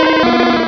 Cri de Salamèche dans Pokémon Rubis et Saphir.
Cri_0004_RS.ogg